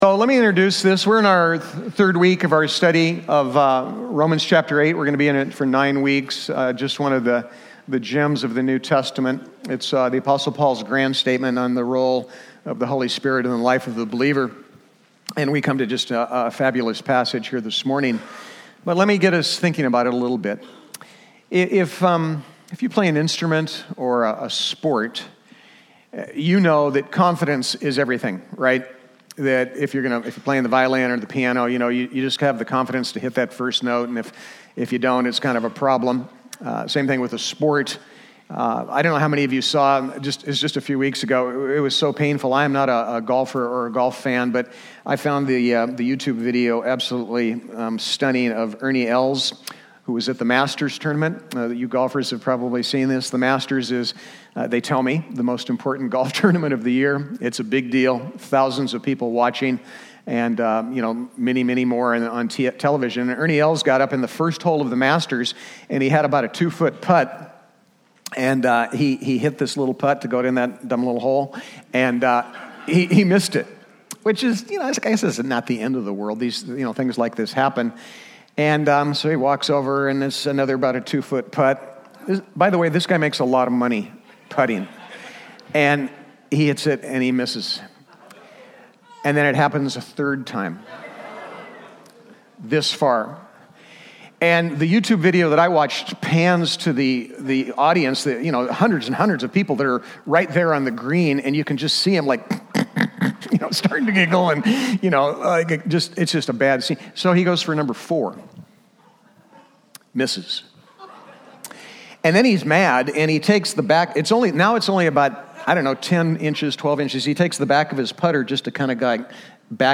Romans 8:12-17 Service Type: Sunday Topics